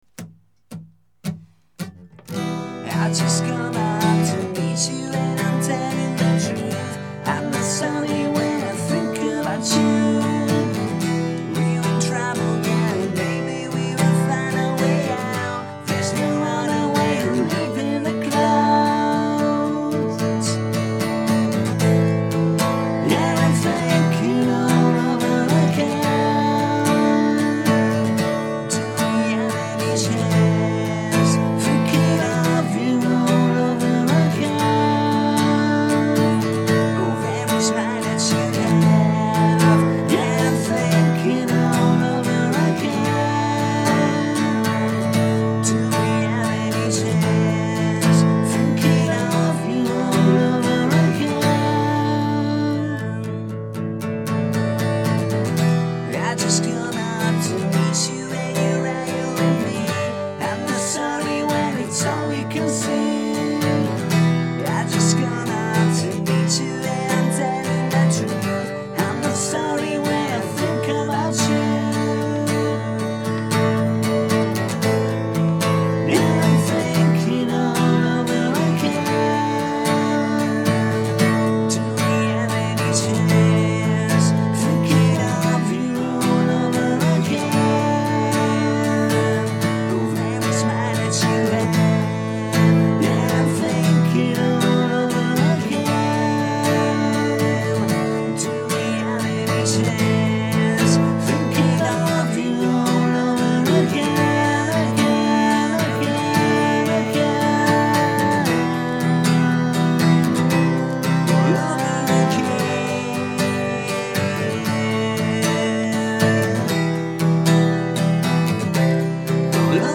un paio di versioni unplugged